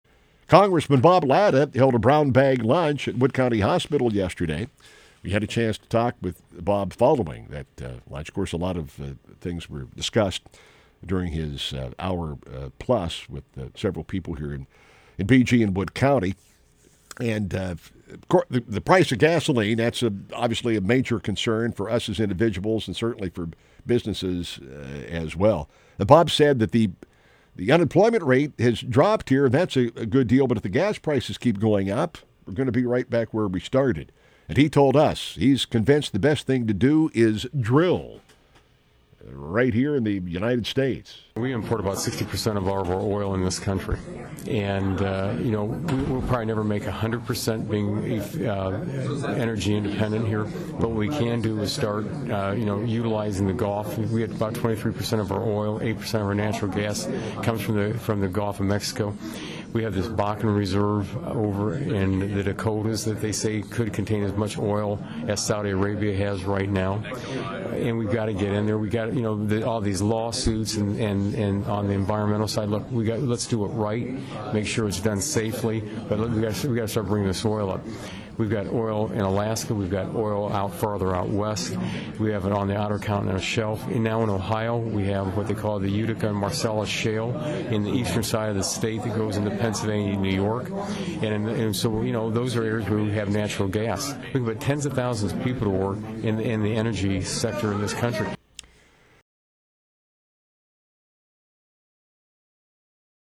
Latta on WBGU discussing energy prices